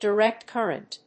アクセントdiréct cúrrent
音節dirèct cúrrent